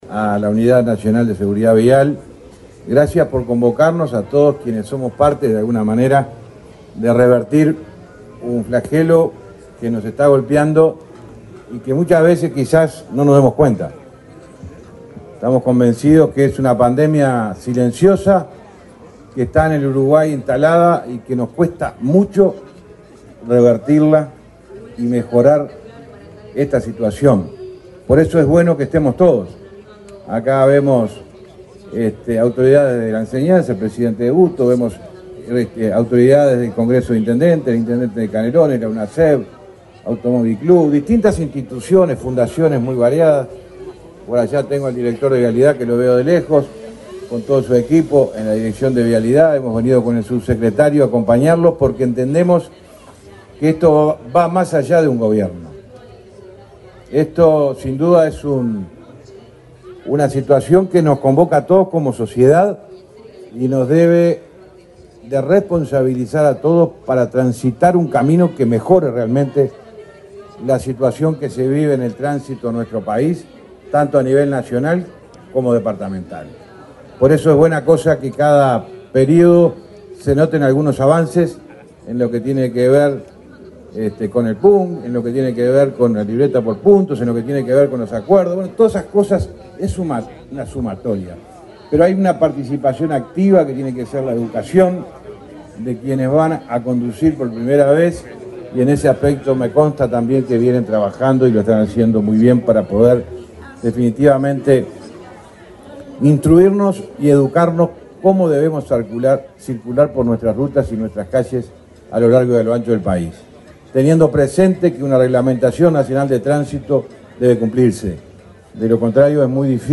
Palabras del ministro de Transporte, José Luis Falero
El ministro de Transporte, José Luis Falero, participó, de la inauguración del stand de la Unidad Nacional de Seguridad Vial en la Expo Prado 2024.